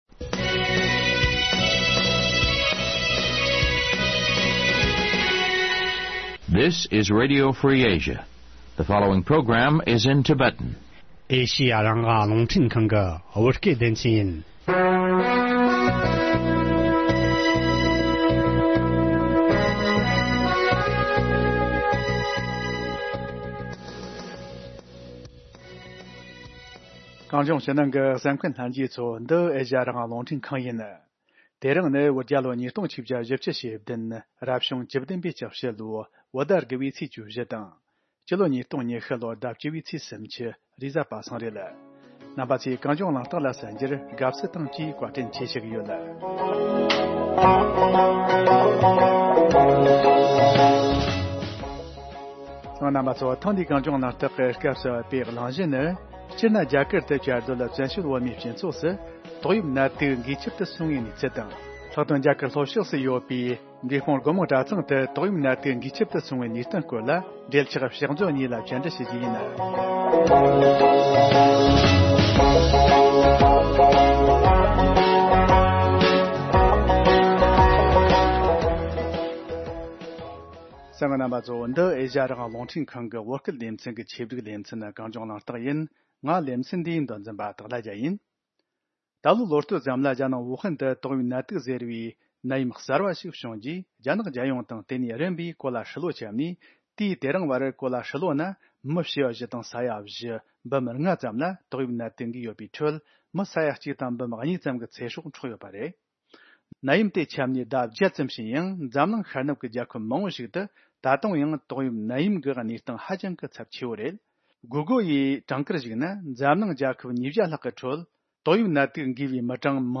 བཅའ་འདྲི་ཞུས་པ།